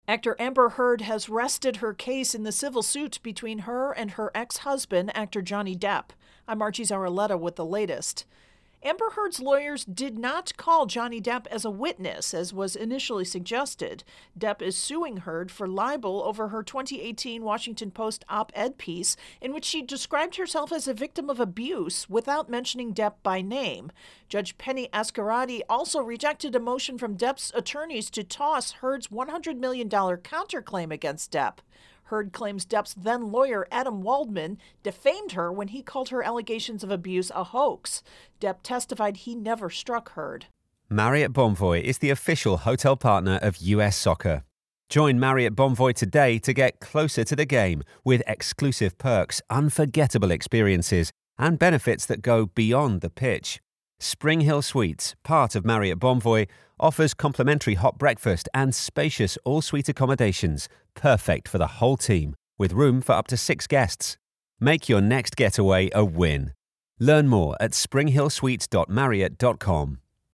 intro & voicer for Depp-Heard Trial